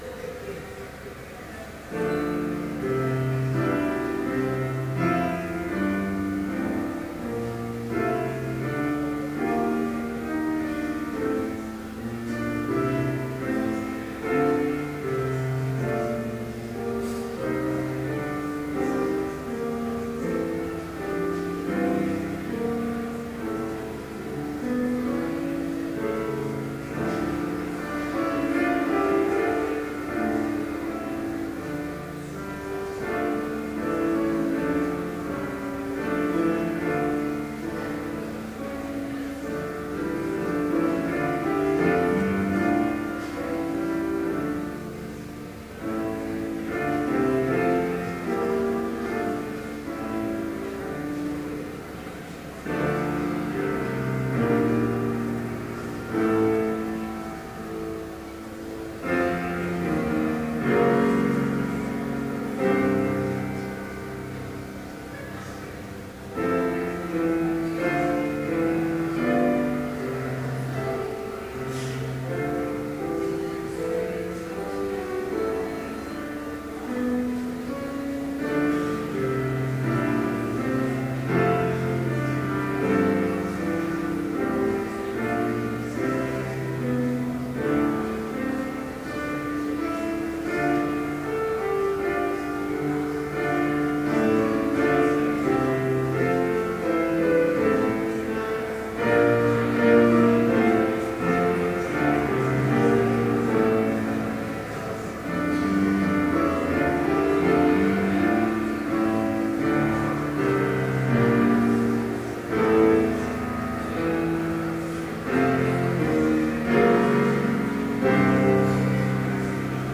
Chapel in Trinity Chapel, Bethany Lutheran College, on October 2, 2012, (audio available) with None Specified preaching.
Complete service audio for Chapel - October 2, 2012